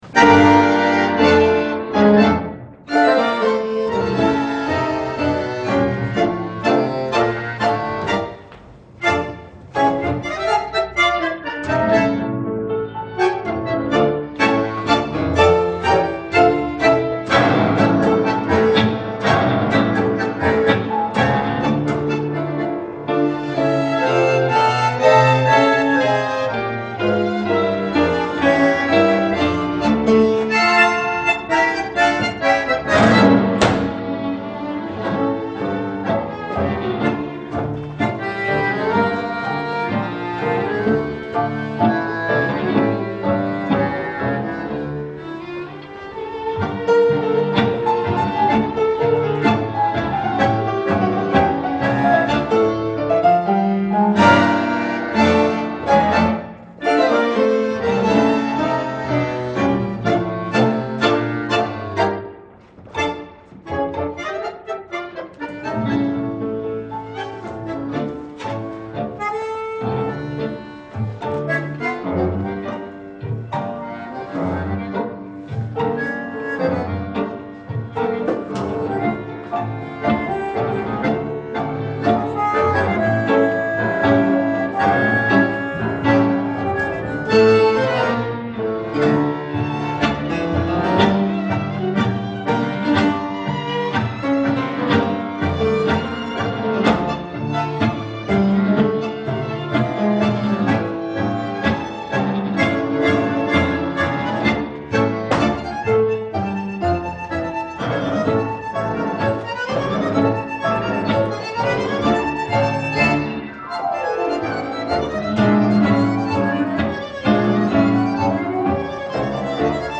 Tango
Group: Dance Origin: Polka , Flamenco , Mazurka , Habanera Subclasses: Milonga Synonyms: Танго A style of ballroom dance music in 2/4 or 4/4 time that originated among European immigrant populations of Argentina and Uruguay Tango Argentina
Tango Argentina.mp3